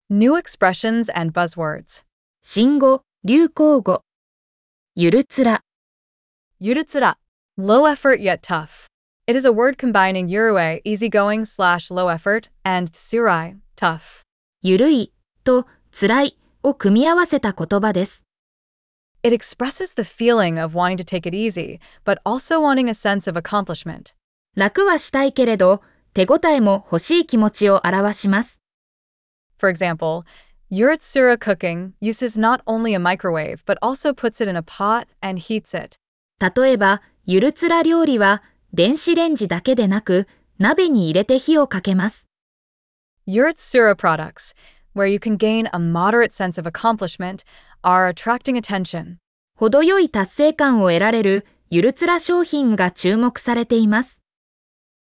🗣 pronounced: Yurutsura